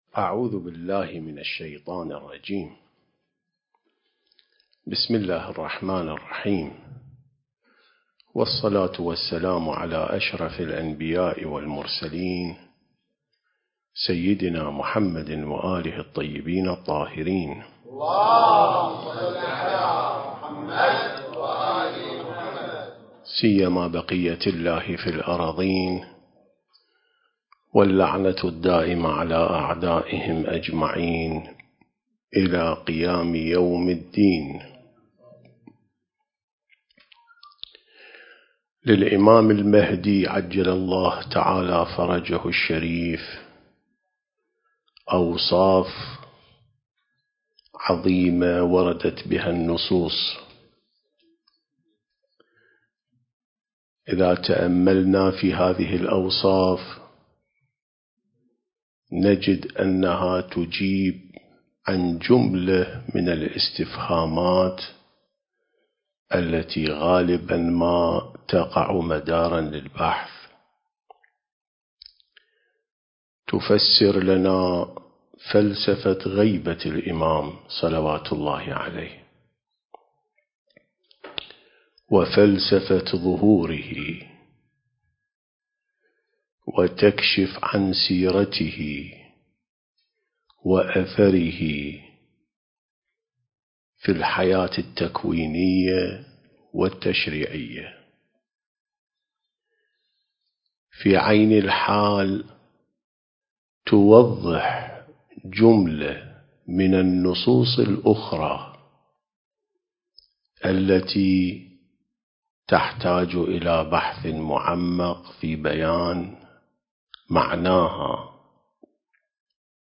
سلسلة محاضرات عين السماء ونهج الأنبياء